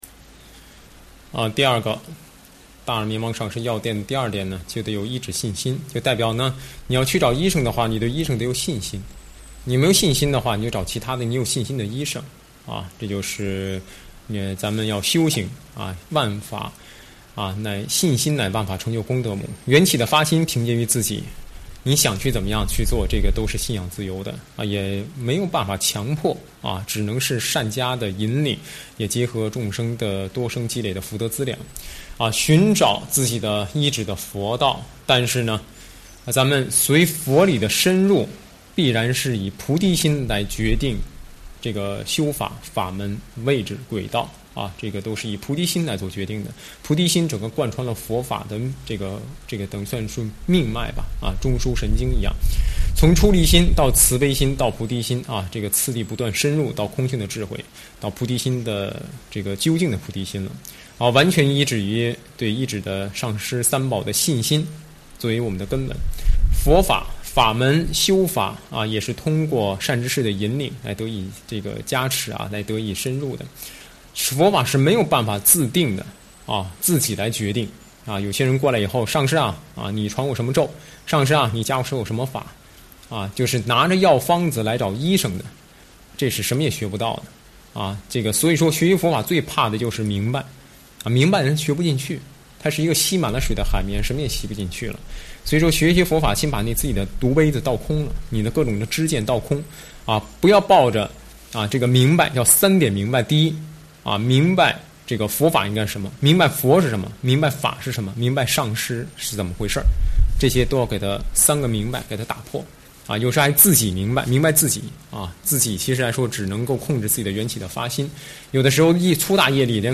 随笔开示